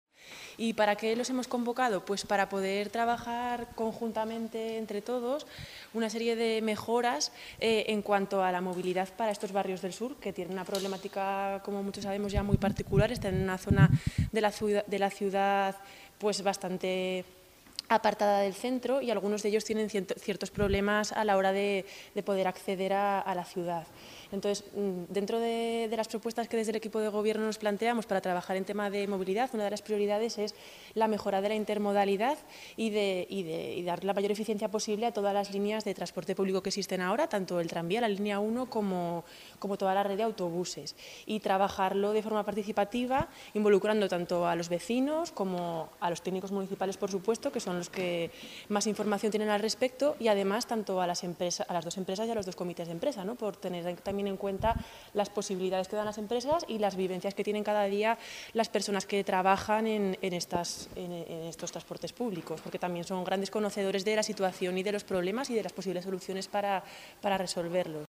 Escuche aquí declaraciones de la Concejala de Medio Ambiente y Movilidad, Teresa Artigas: